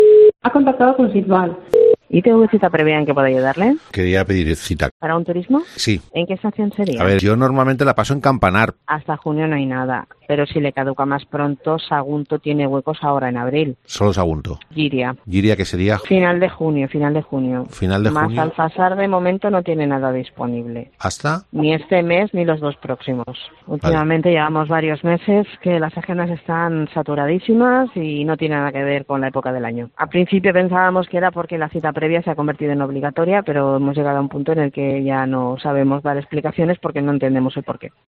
Petición telefónica para cita ITV